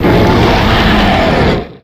Cri de Zygarde dans sa forme 50 % dans Pokémon X et Y.